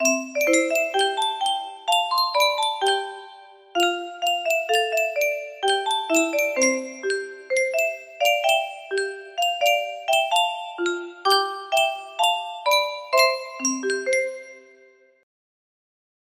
Yunsheng Music Box - Unknown Tune 2371 music box melody
Full range 60